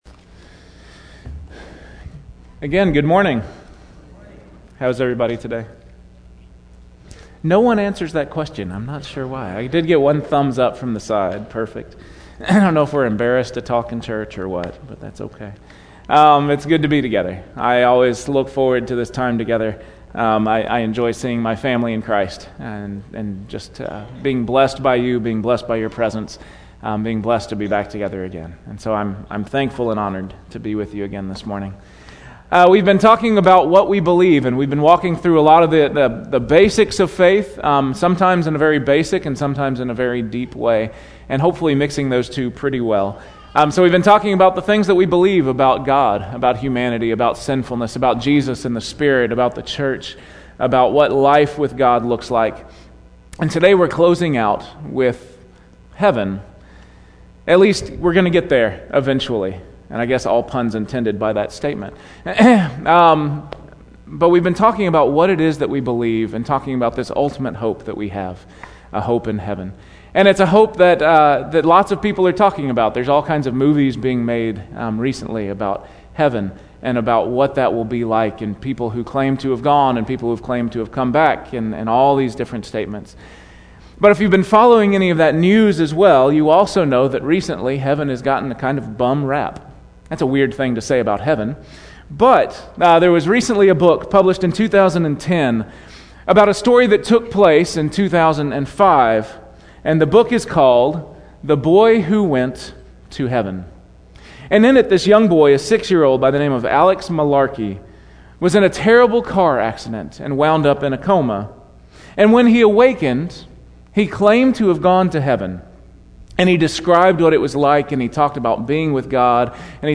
Weekly Sermon Audio “What We Believe About…